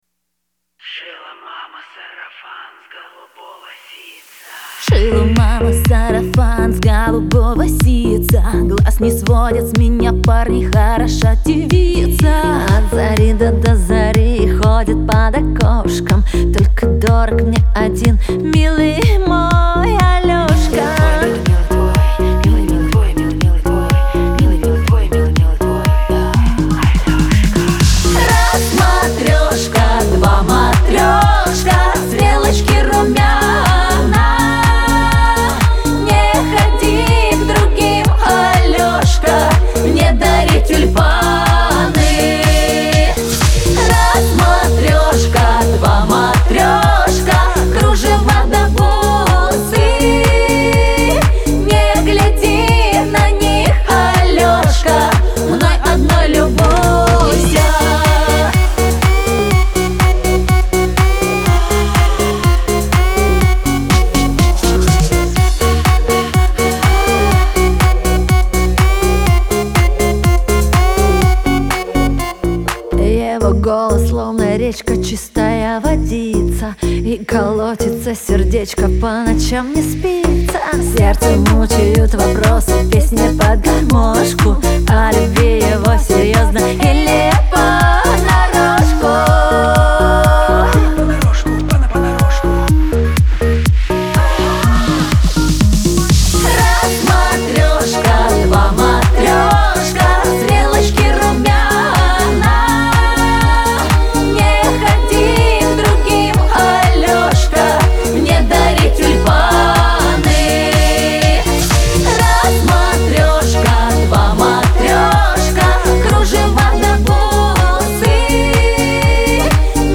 Веселая музыка , эстрада , диско